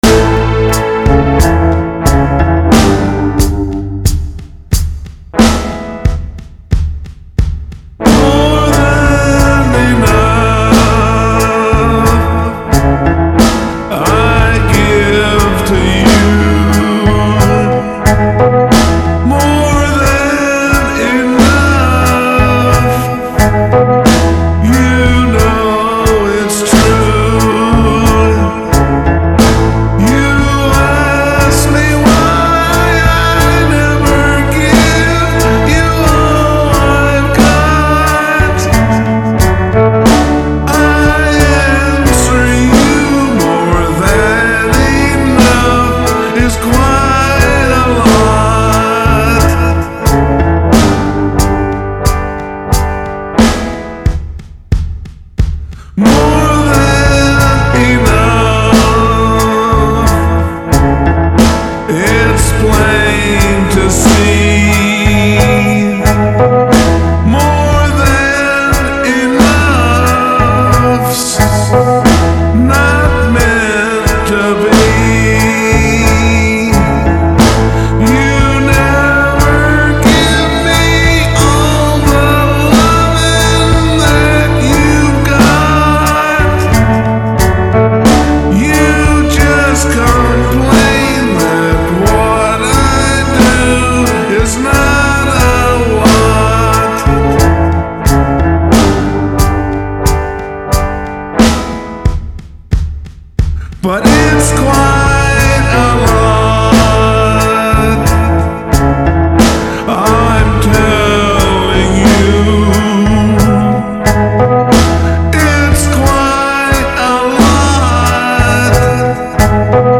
I wrote a third verse for the new Country Western song and recorded a prototype lead vocal, which included doing a bit of producing for the various instruments and adding some reverberation and custom echoes . . .
-- Basic Rhythm Section and Lead Vocal -- MP3
I am pondering the idea of adding strings, since there is a bit of space at the end of the song where currently there is only kick drum, but I like the raw electric guitar, so I plan to do some experiments to make the electric guitar a bit more present or whatever, and I might do the electric guitar part with a real electric guitar, although I like the NOTION 4 bundled Electric Guitar, which I ran through a double Fender amplifier rig with a tremolo pedal in AmpliTube 3 (IK Multimedia) to get "bright" Fender TONE . . .
It is a Country Western song, and angst always is a good theme, which is fabulous . . .